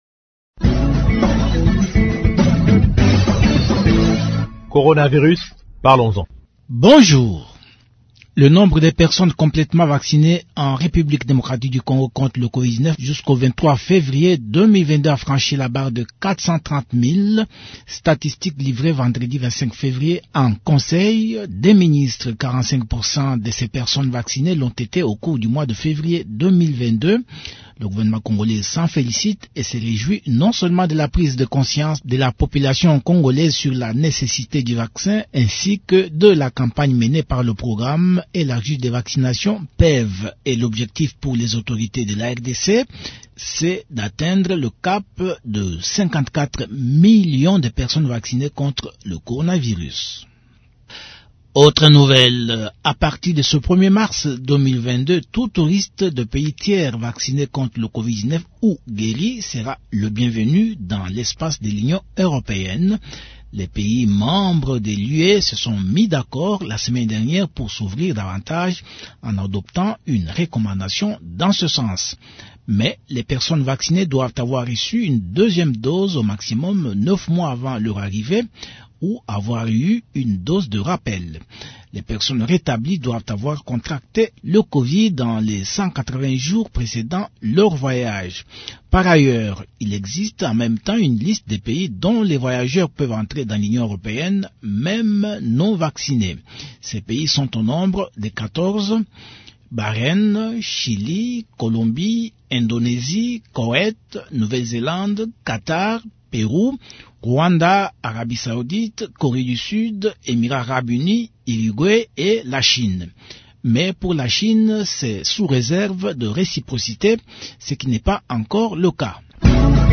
Le ministre provincial de la santé du Kwilu, le Dr. Bena Mutuy, évoque la question de la rupture de stock du vaccin Pfizer.